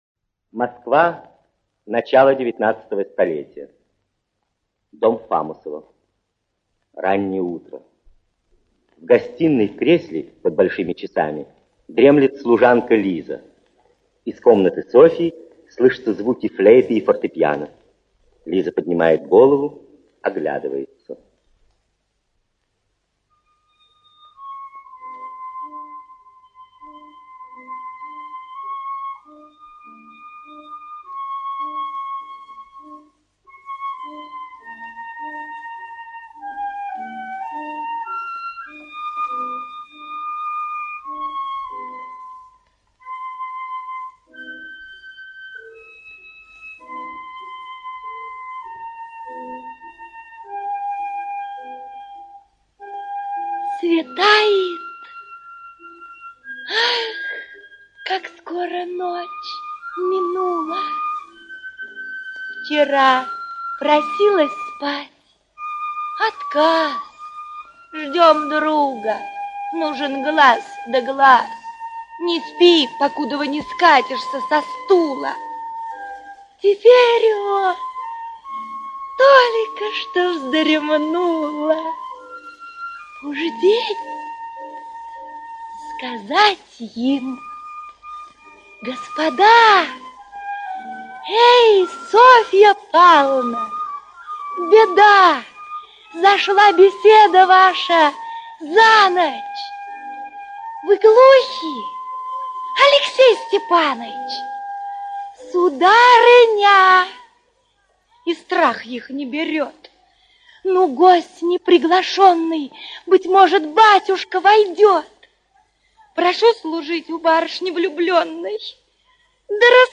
Aудиокнига Горе от ума Автор Александр Грибоедов Читает аудиокнигу П. М. Садовский.